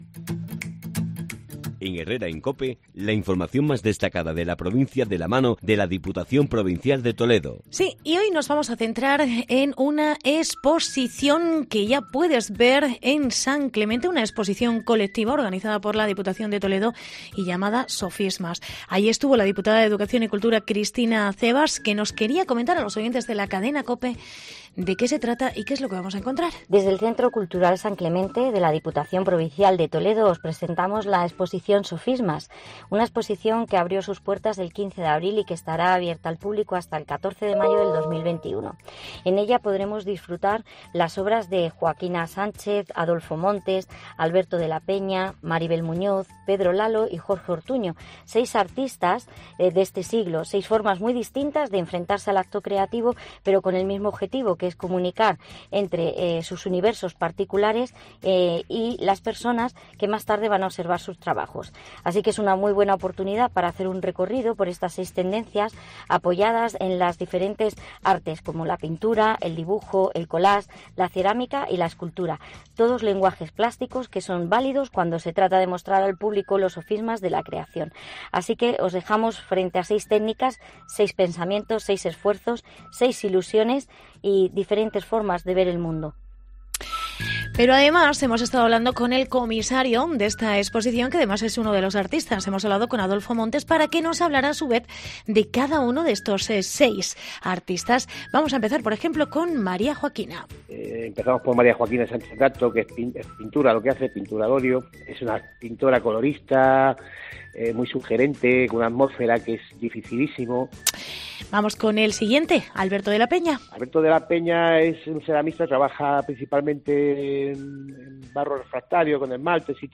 Reportaje exposición "Sofismas" en el Centro Cultural San Clemente